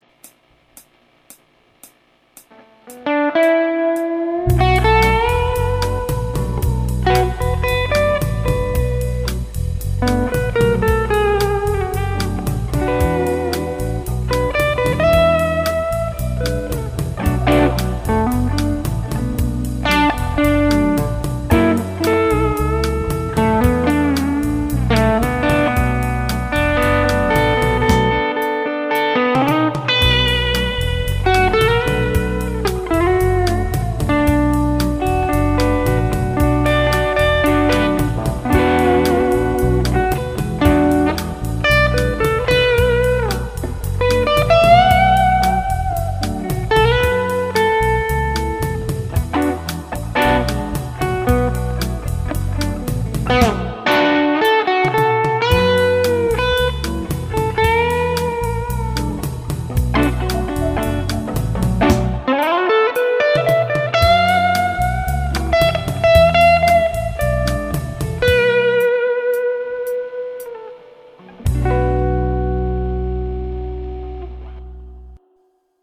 Tämä on rootsskaba roots-musiikista kiinnostuneille, joilla ei välttämättä ole taitoa tai kokemusta, jotta julkaisukynnys ylittyisi olemassa oleviin (roots, jazz, soolo, bassolinja) skaboihin.
- soita soolosi annetun taustan päälle